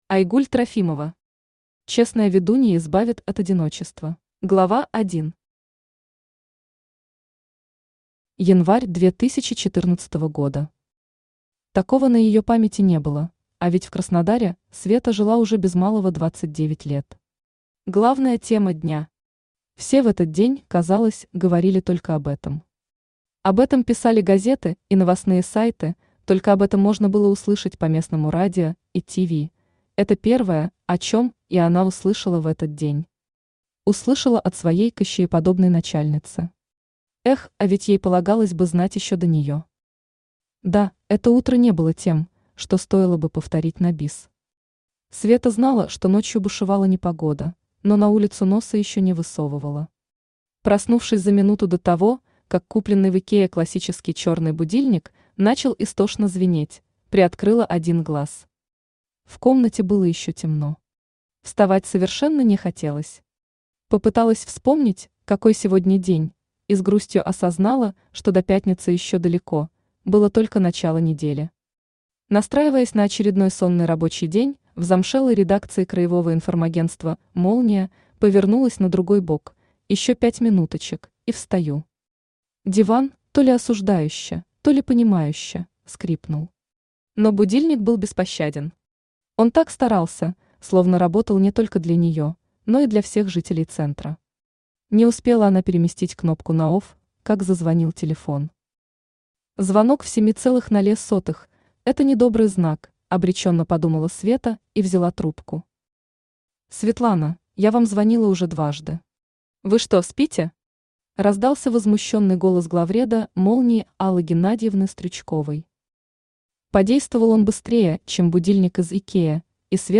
Aудиокнига Честная ведунья избавит от одиночества Автор Айгуль Трофимова Читает аудиокнигу Авточтец ЛитРес.